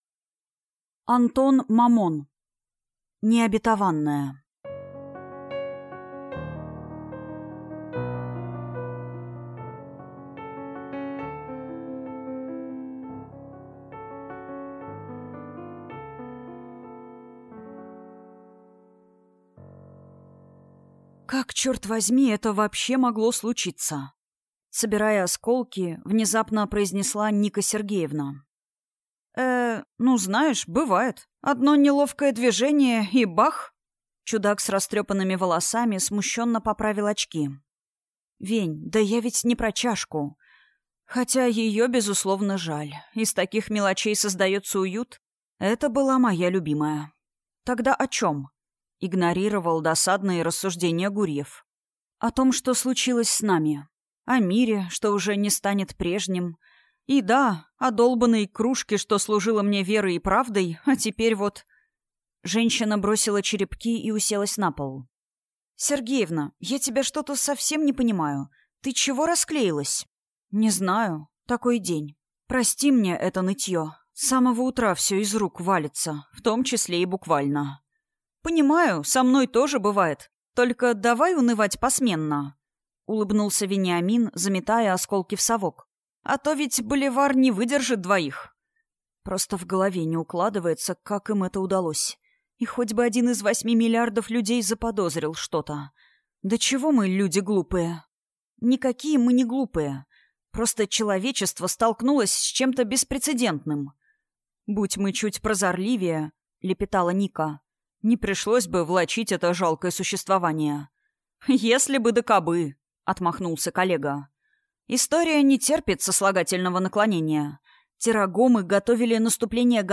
Аудиокнига Необетованная | Библиотека аудиокниг